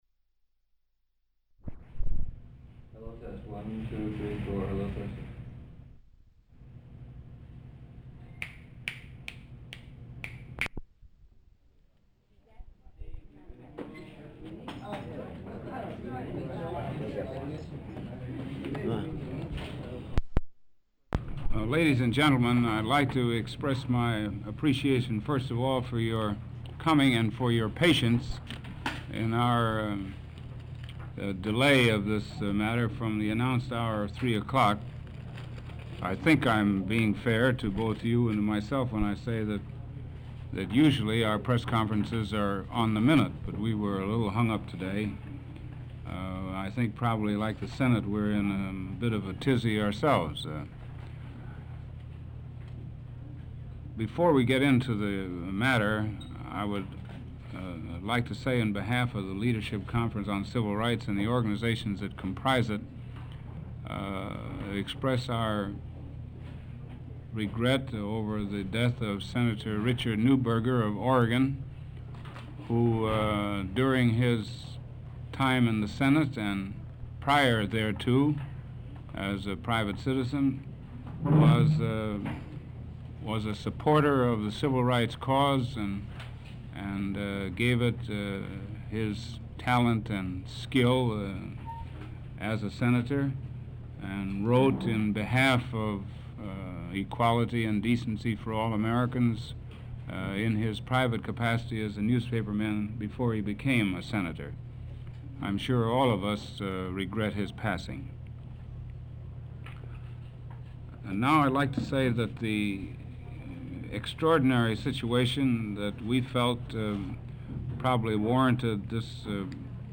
Walter P. Reuther Digital Archive · Press Conference on Civil Rights Legislation, Tape 1 (of 2) · Omeka S Multi-Repository